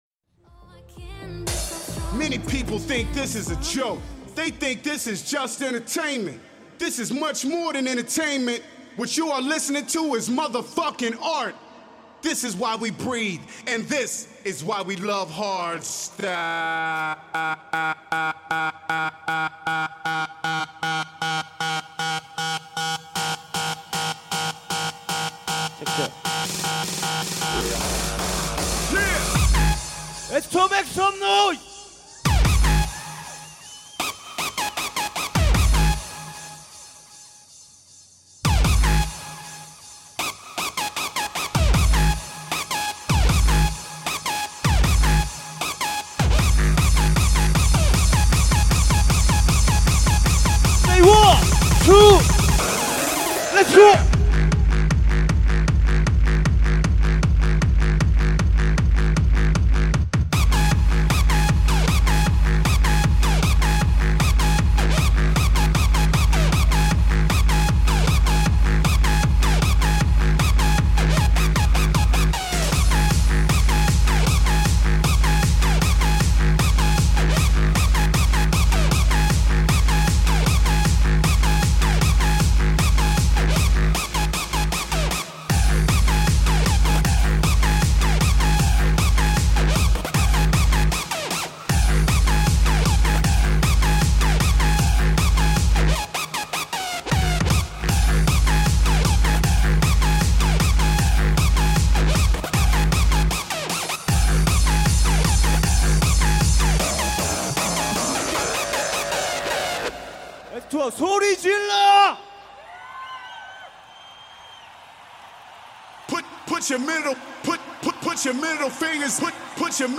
Also find other EDM Livesets, DJ Mixes and